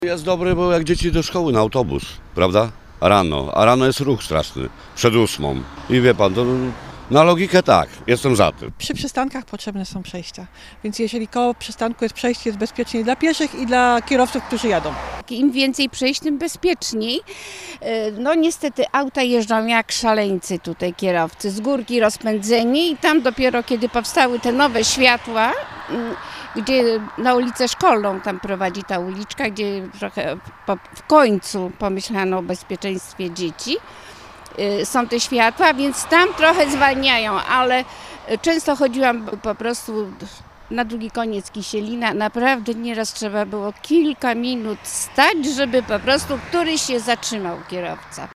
Mieszkańcy zdecydowanie popierają pomysł wyznaczenia przejścia dla pieszych: